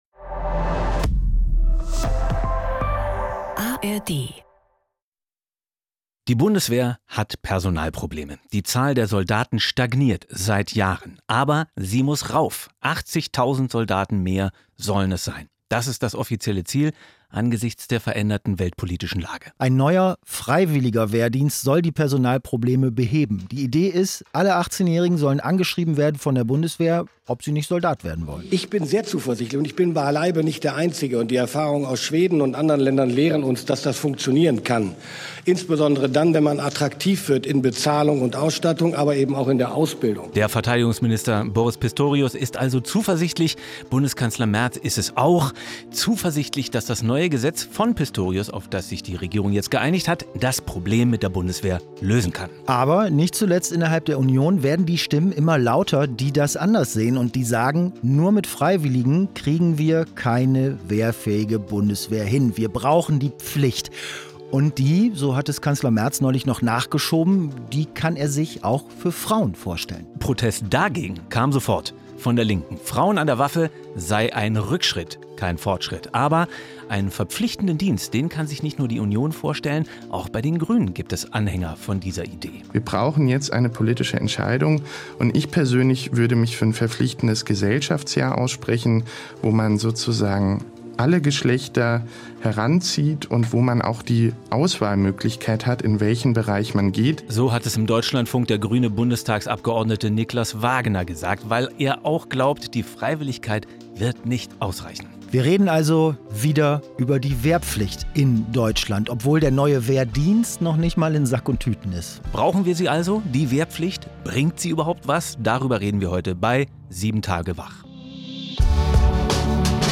Zwei Freunde, zwei Meinungen, ein News-Podcast